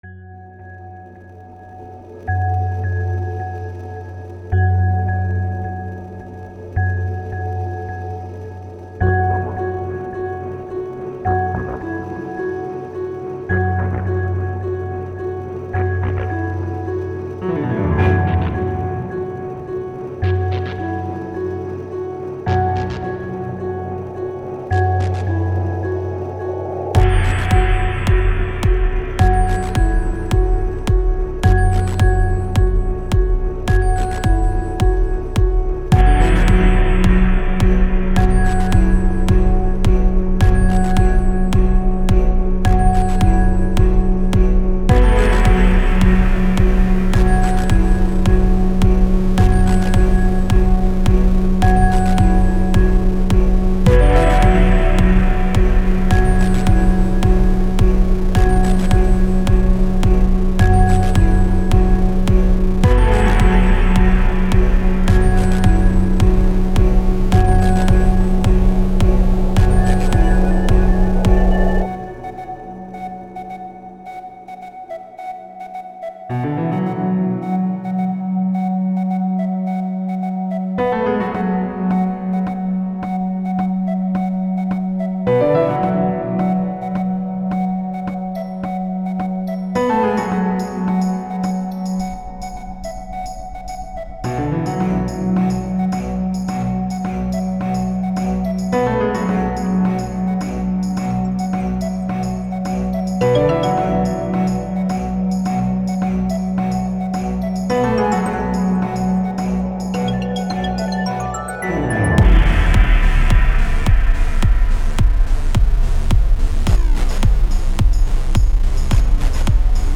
I can't say this is similar to anything I've made before with the kinda minimalist techno & miscellaneous instruments. but ...
Music / Techno
electro electric electronic house ethnic oriental original orchestral classical bass beat beats minimal techno tech
It has a nice beat and is mellow.